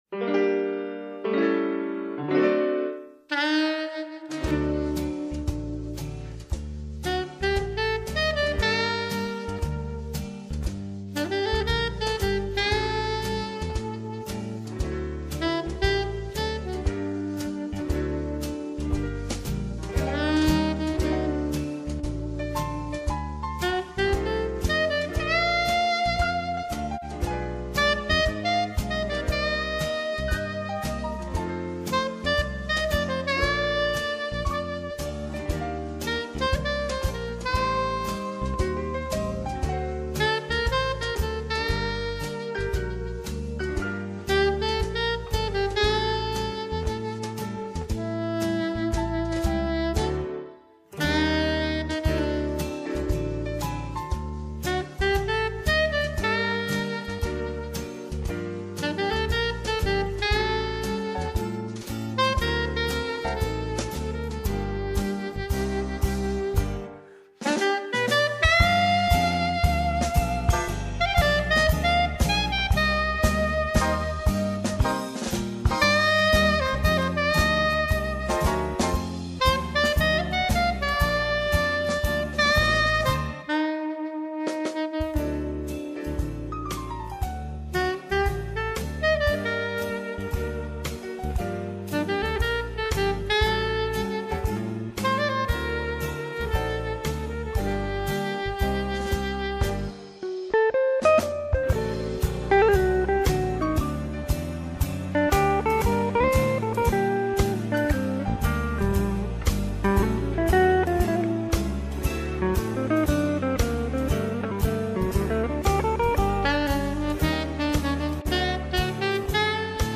TOUT LE TALENT D’UN SAXOPHONISTE
Saxophones Sopranos :